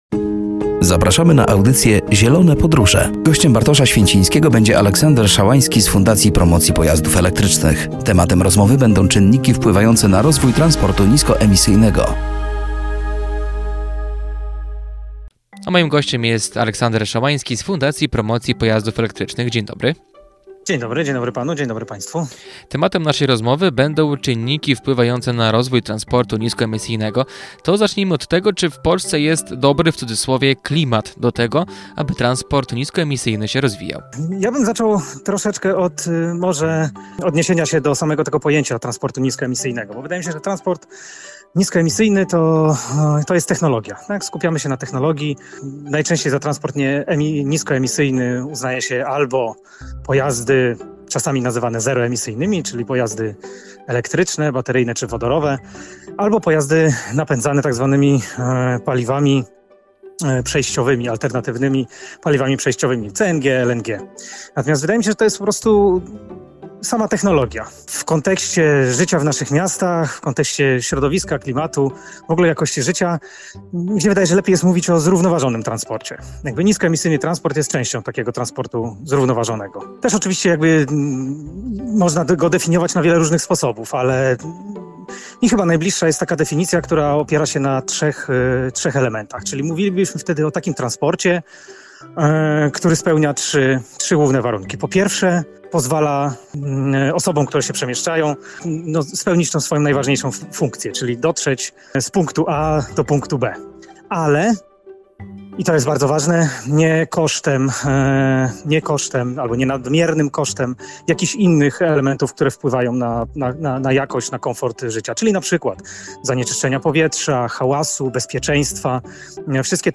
Rozmowa dotyczyła czynników wpływających na rozwój transportu niskoemisyjnego. Czy w Polsce jest możliwy zrównoważony rozwój bardziej ekologicznego transportu?